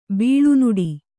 ♪ bīḷu nuḍi